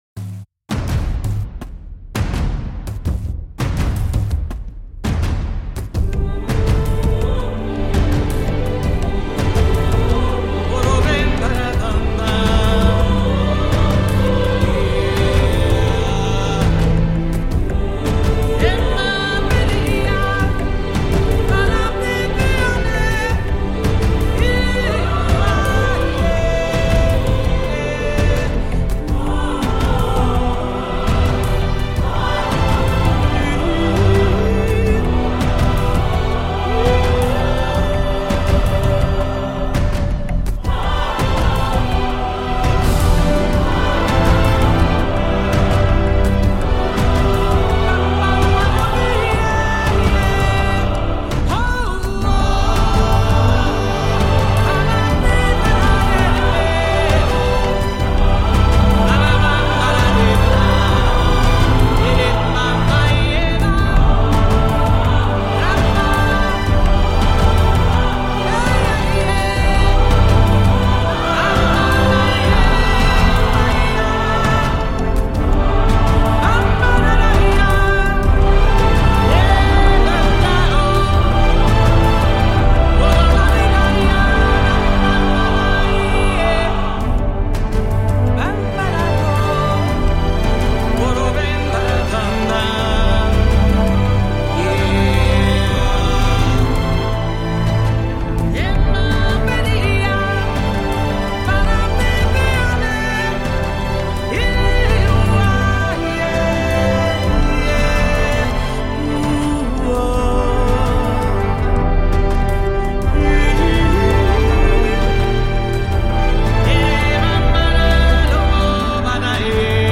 percussions et voix africaines sont de mise